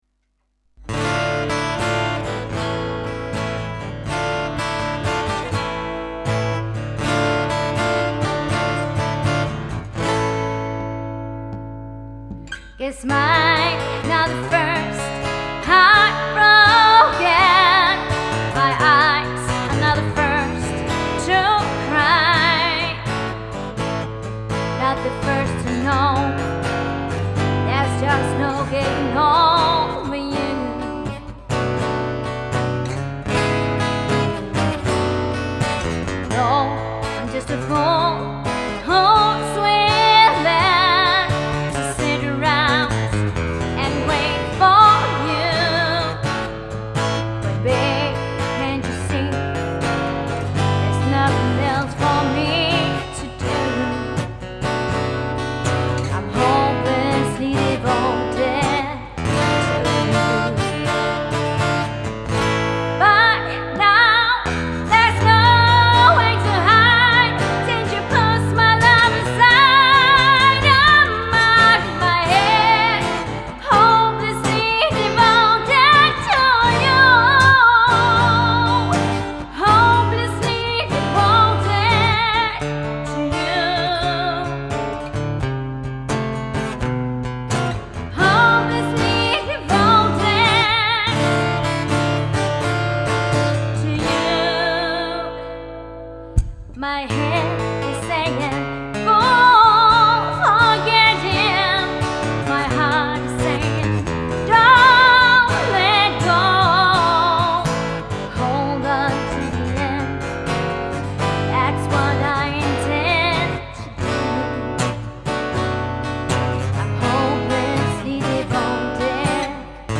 • Country
• Dansktop
• Pop